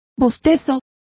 Complete with pronunciation of the translation of yawn.